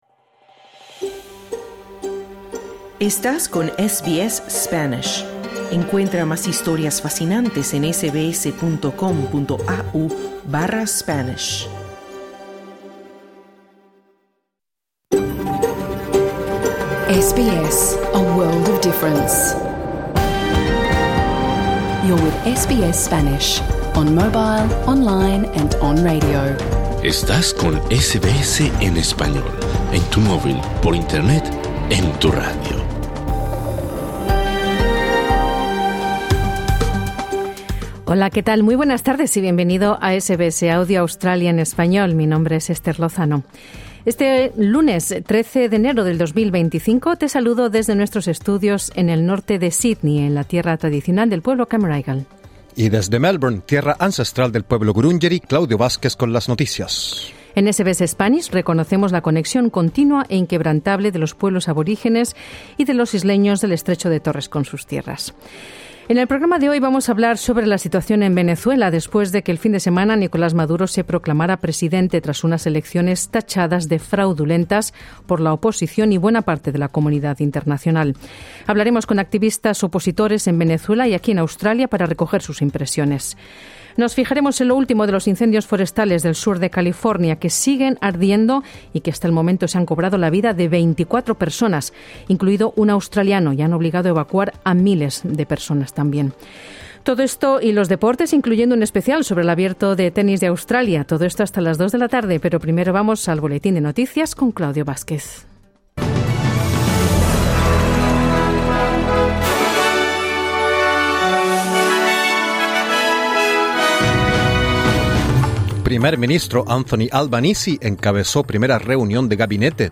Programa en vivo | SBS Spanish | 13 enero 2025